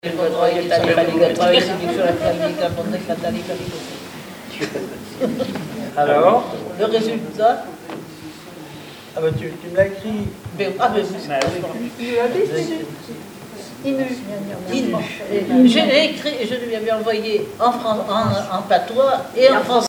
Devinette - Le bareuille
Langue Patois local